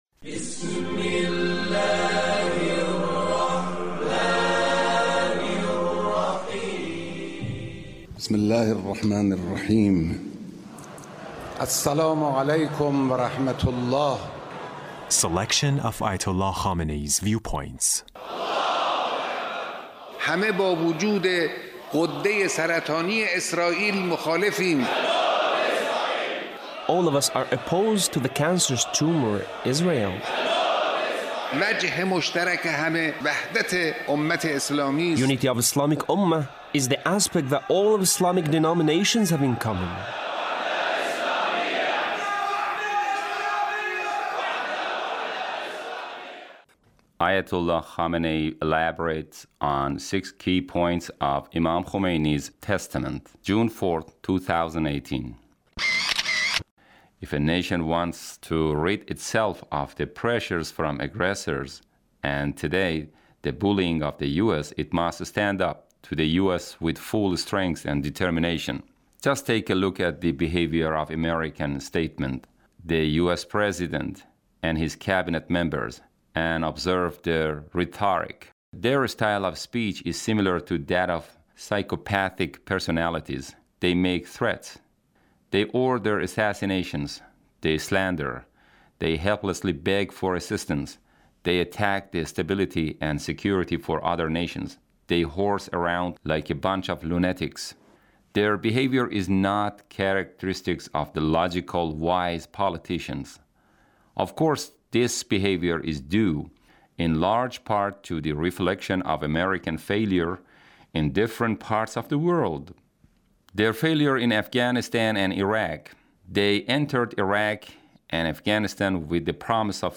Leader's Speech (1758)